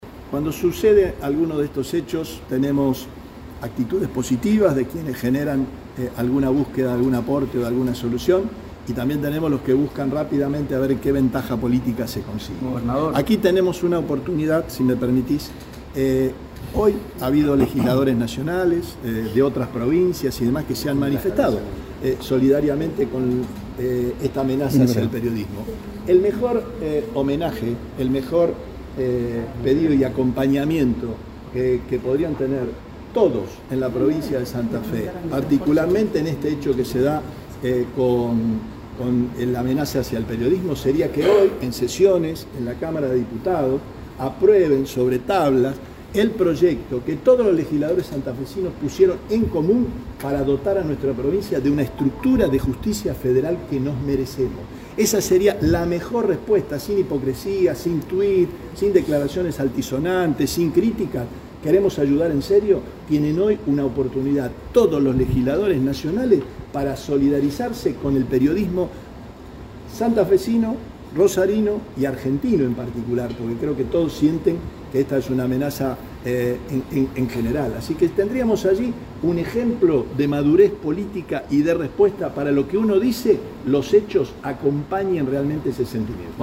Audio-Perotti-Rueda-de-Prensa.mp3